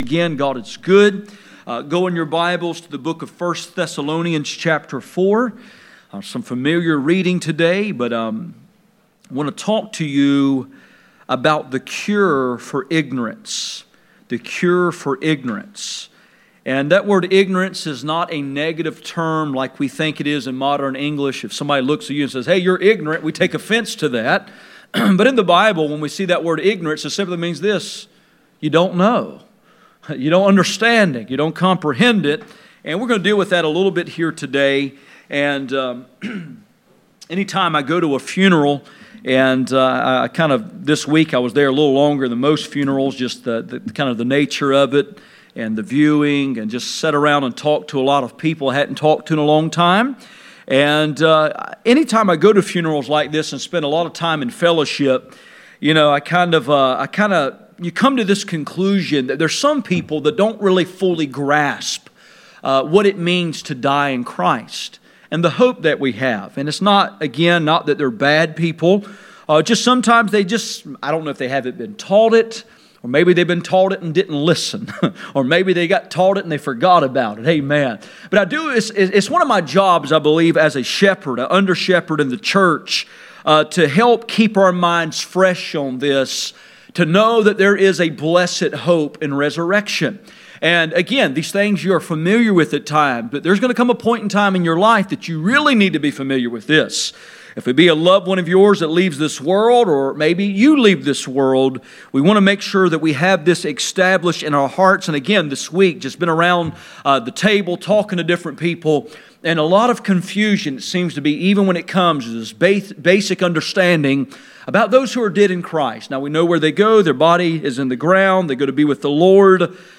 None Passage: 1 Thessalonians 4:13-1 John 4:18 Service Type: Sunday Morning %todo_render% « The God of ALL Comfort The cure for ignorance